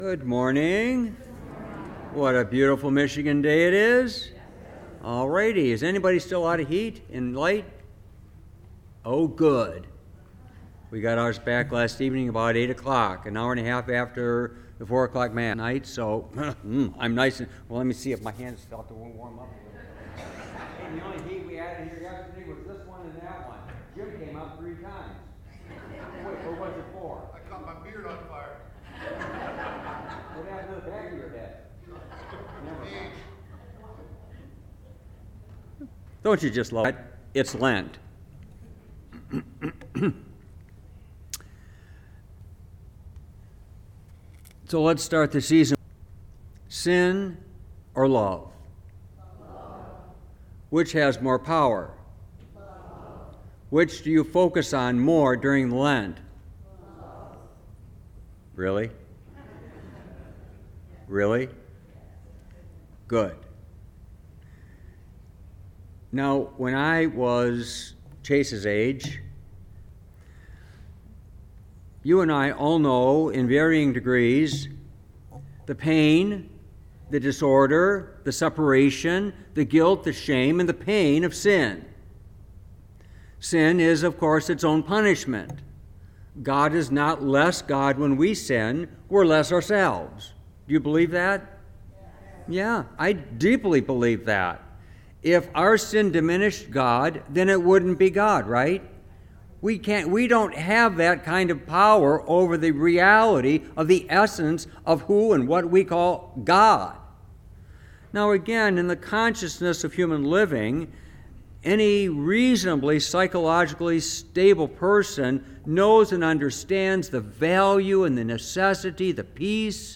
Homily, February 26, 2023